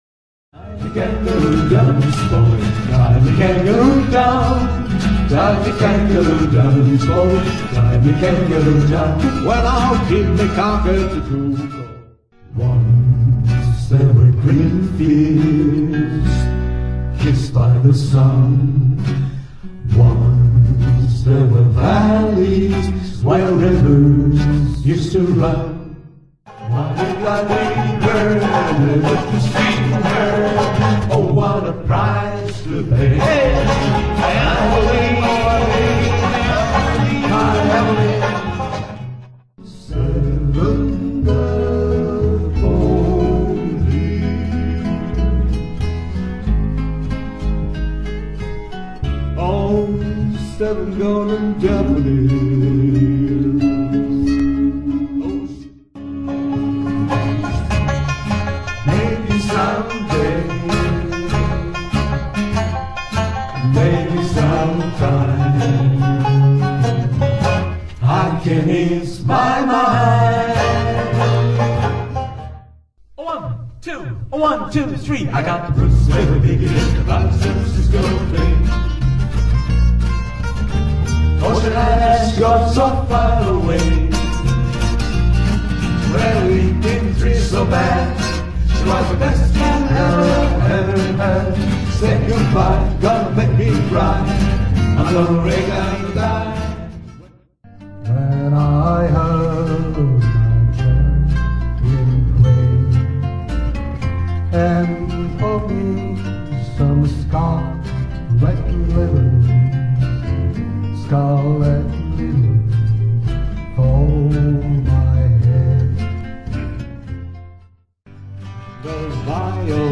一方、レバリーズフォーは、３月１２日（日）新所沢の貸スタジオを４時間借り切って、ライブ演奏曲１０数曲を練習。
練習の模様はこちらの録音です。